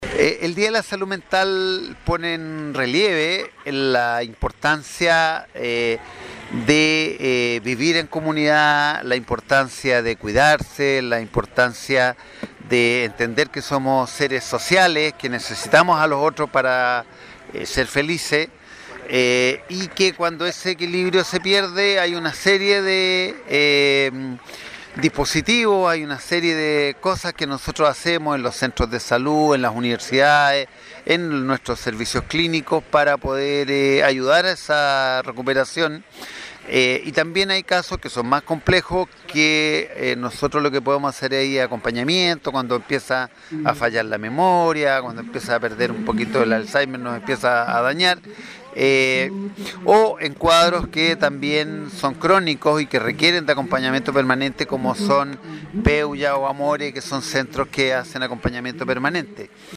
Así lo expresó el Director del Servicio de Salud Osorno, Rodrigo Alarcón: